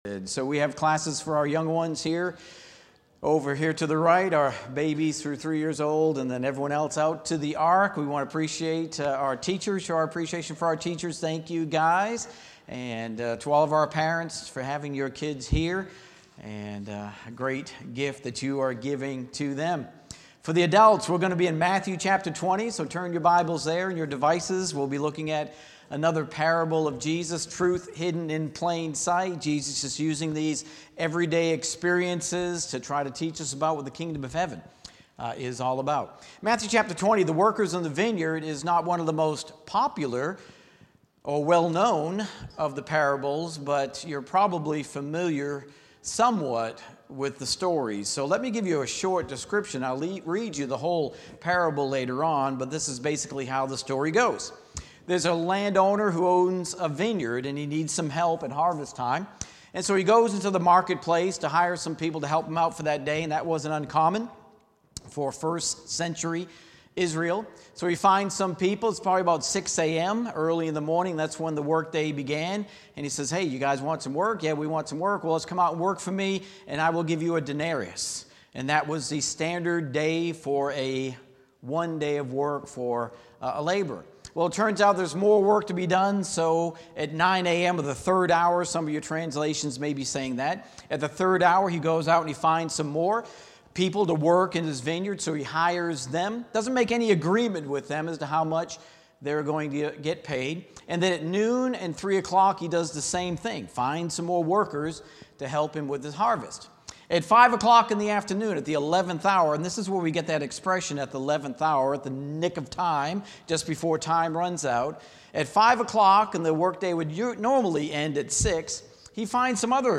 Sermons | Park Avenue Church of Christ